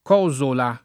[ k 0@ ola ]